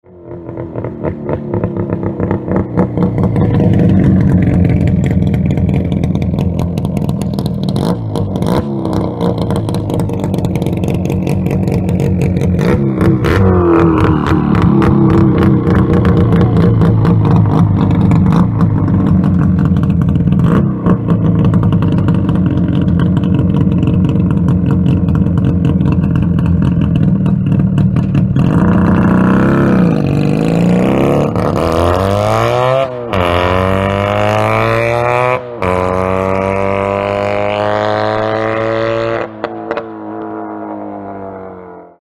Звуки мотоциклов
Звук двигателя Явы: особенности и характеристики